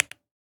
key-press-1.ogg